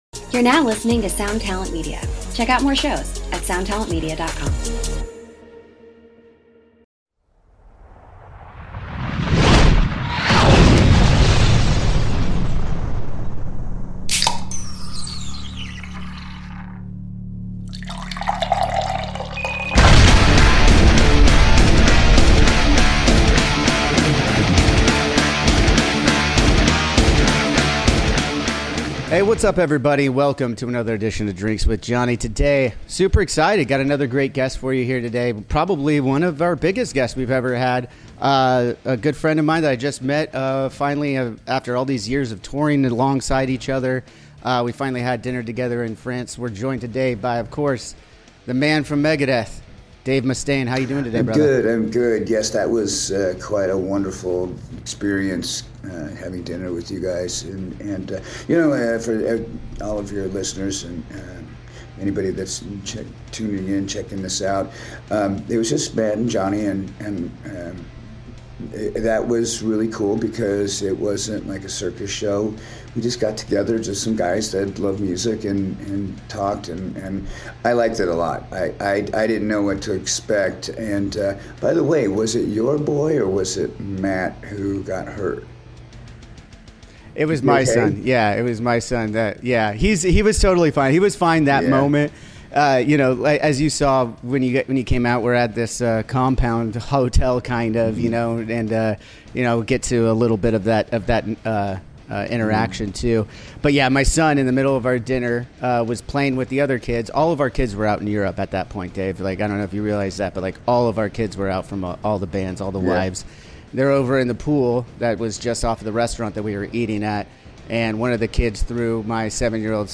This week we are joined by metal royalty as Dave Mustaine sits down to have a chat with Johnny Christ. The two talk about crossing paths over the years and how they came to finally meet in France before Megadeth and Avenged Sevenfold would share the stage at Hellfest.